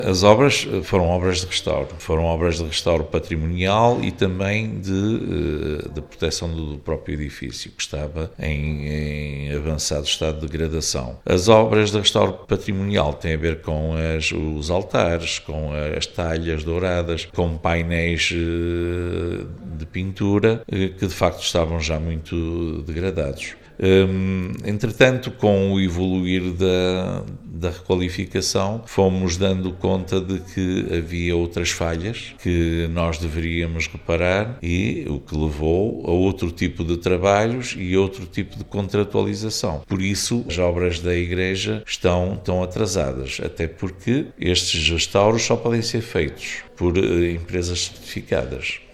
Ainda outro pedido de esclarecimentos, foram no âmbito das obras da Igreja Matriz de Podence, sobre o cronograma das mesmas e quais são os constrangimentos que estão a sofrer. O autarca adianta que as obras são especializadas e certificadas no altar-mor:
reuniao-de-camara-3.mp3